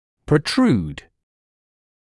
[prə’truːd] [прэ’труːд] перемещать вперед (о зубе, гл.об. корпусно),